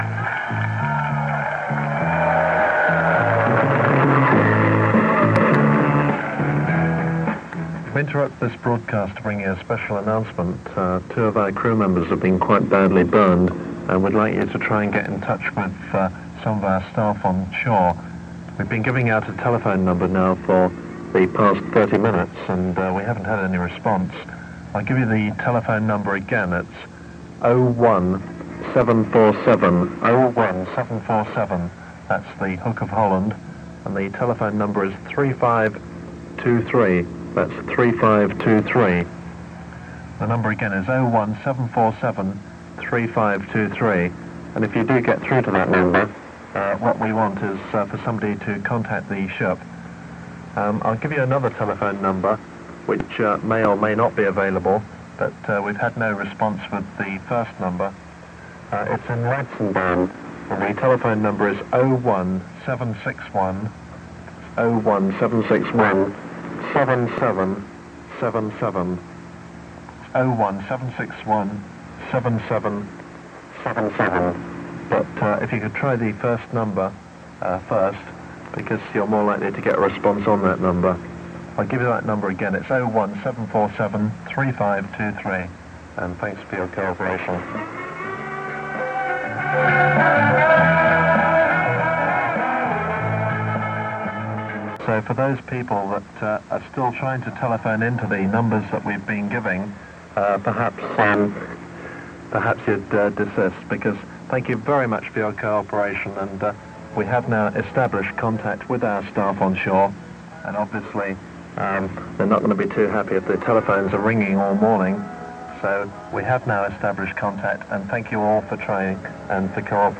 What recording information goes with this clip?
interrupts the programmes of Radio Mi Amigo to appeal for help after two crew members suffer burns in an accident